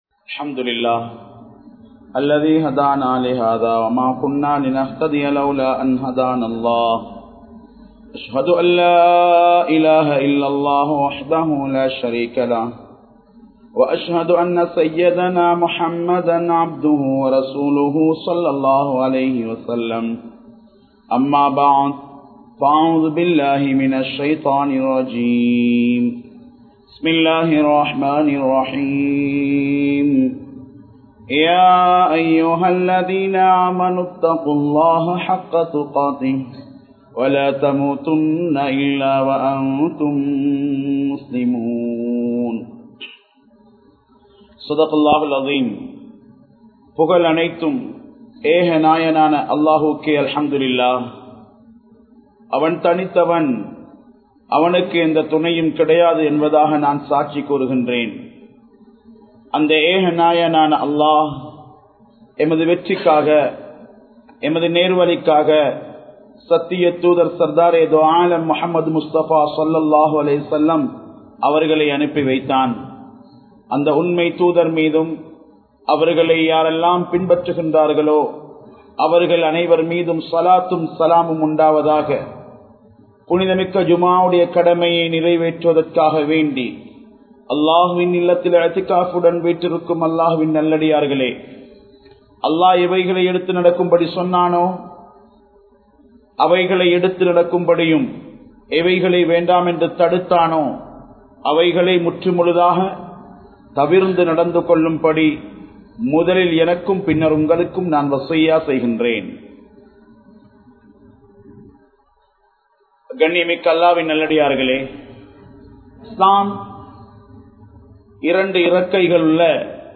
Petroarhalin Saafaththai Payanthu Kollungal (பெற்றோர்களின் சாபத்தை பயந்து கொள்ளுங்கள்) | Audio Bayans | All Ceylon Muslim Youth Community | Addalaichenai
Delgahagoda Jumua Masjidh